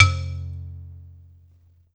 Index of /90_sSampleCDs/USB Soundscan vol.02 - Underground Hip Hop [AKAI] 1CD/Partition D/06-MISC
BALAFON 2 -L.wav